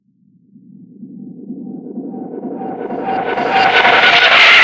FADEINREV -R.wav